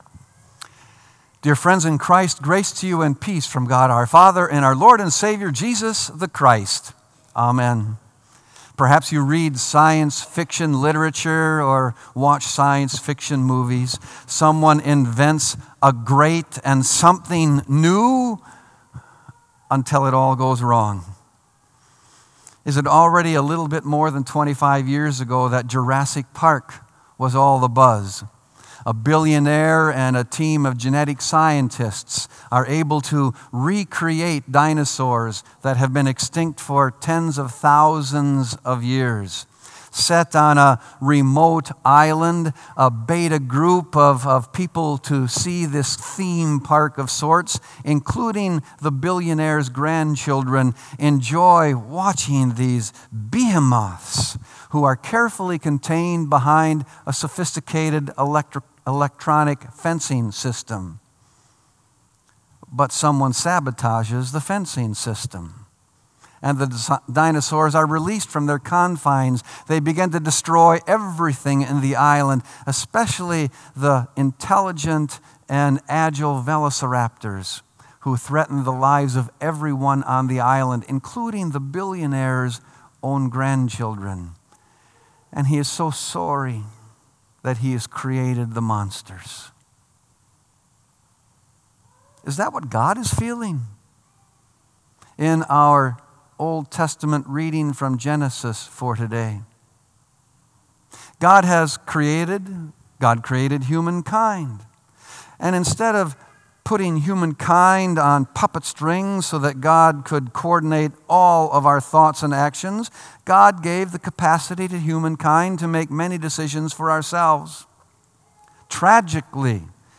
Sermon “Re-Creation”